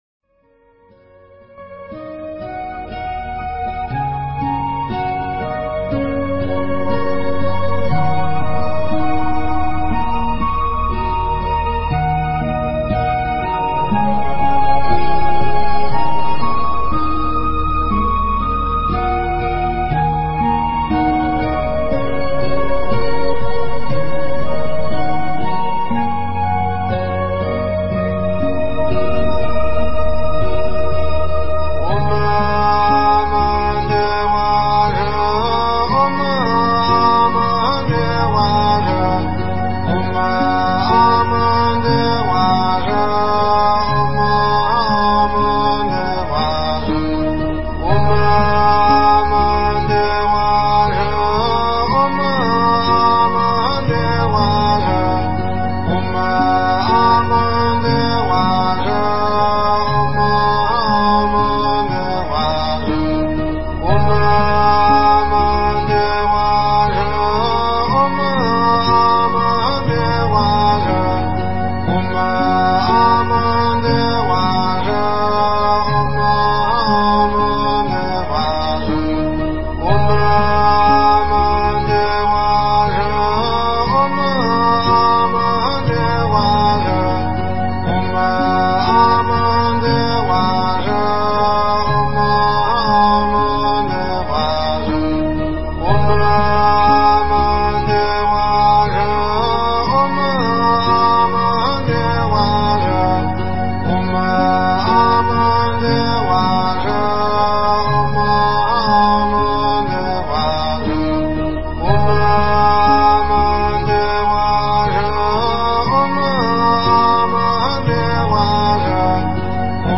佛音 诵经 佛教音乐 返回列表 上一篇： 观音偈 下一篇： 观音菩萨偈 相关文章 般若波罗密多心经-悉昙古音版 般若波罗密多心经-悉昙古音版--佚名...